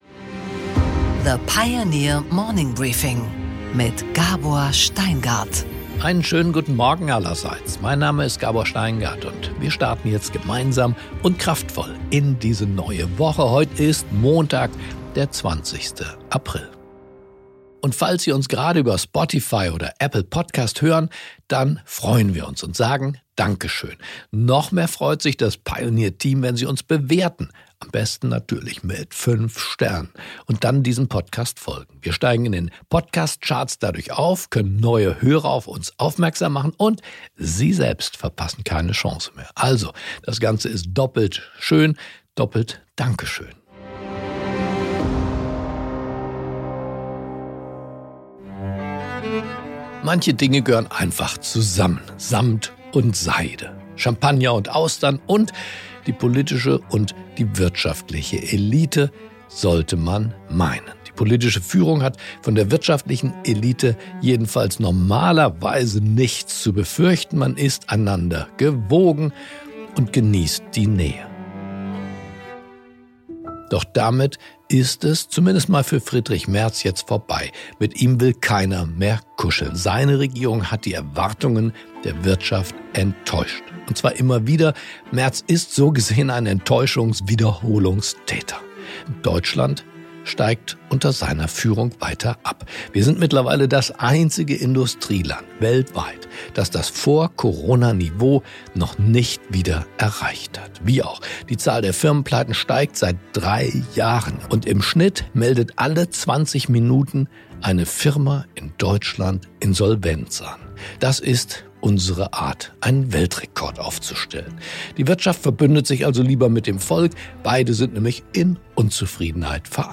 Gabor Steingart präsentiert das Morning Briefing.